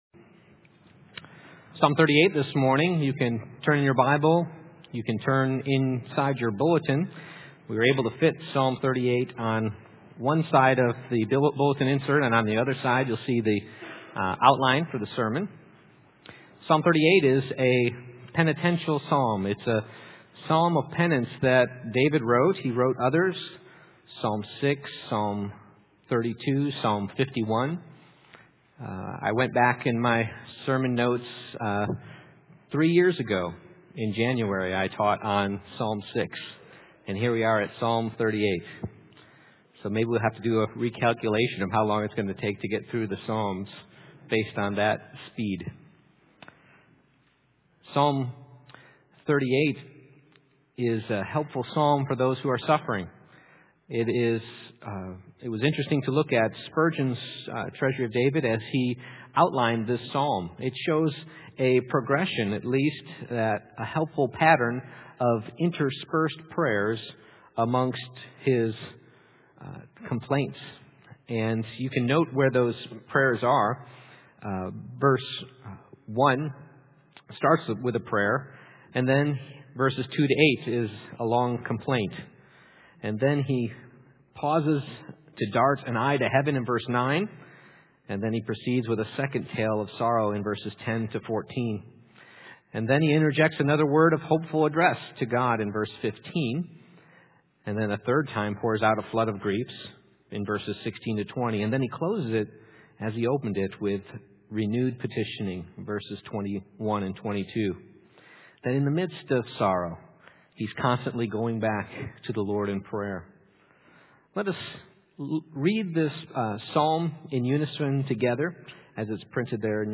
Passage: Psalm 38:1-22 Service Type: Morning Worship